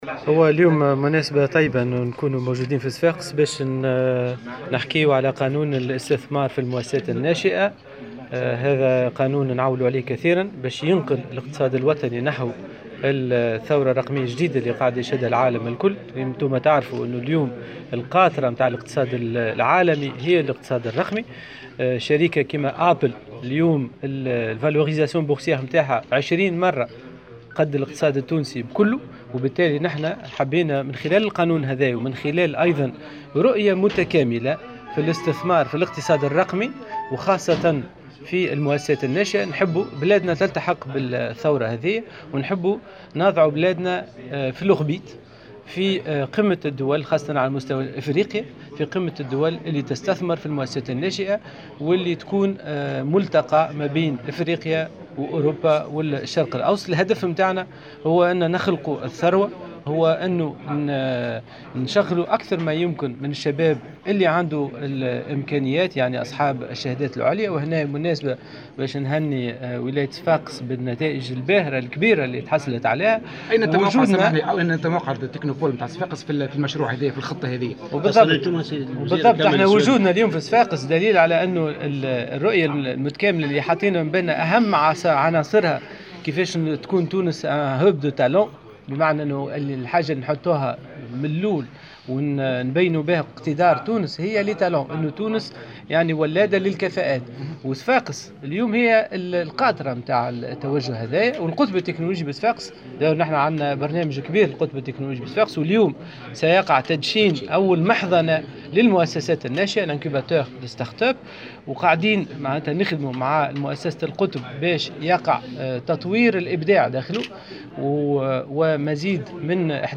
وجاءت تصريحاته على هامش افتتاحه اليوم في صفاقس المنتدى العاشر للاستثمار تحت شعار الاستثمار في الاقتصاد الرقمي، بحسب مراسل "الجوهرة أف أم".